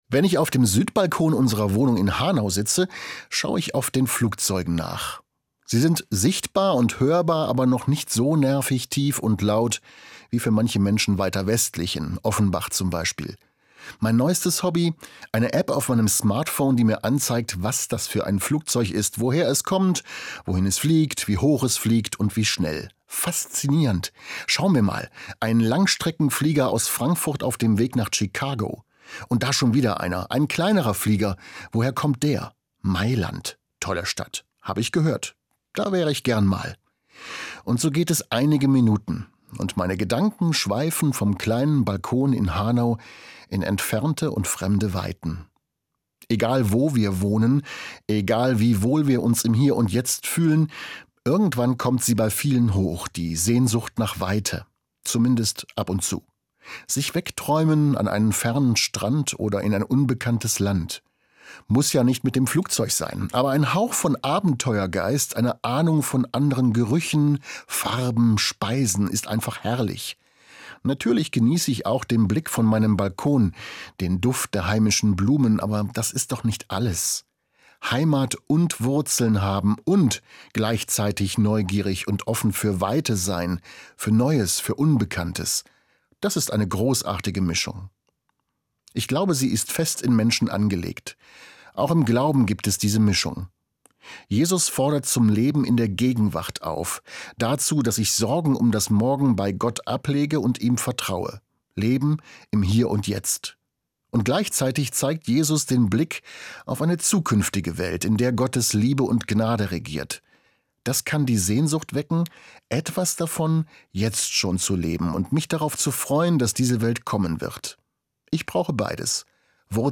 Pastor, Hanau